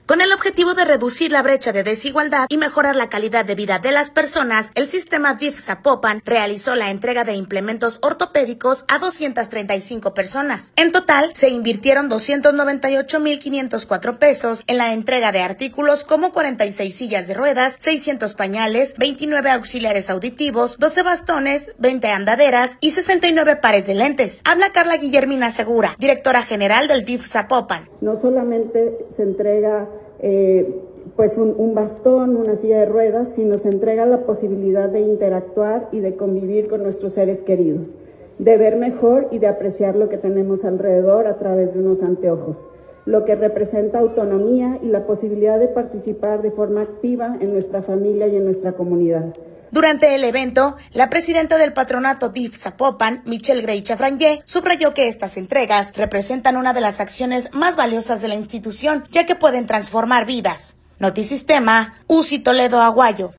Habla Karla Guillermina Segura, directora general de DIF Zapopan.